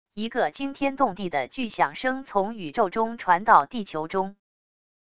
一个惊天动地的巨响声从宇宙中传到地球中wav音频